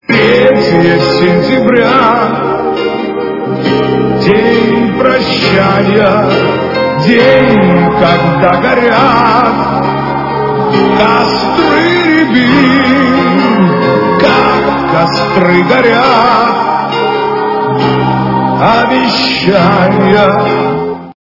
русская эстрада
качество понижено и присутствуют гудки.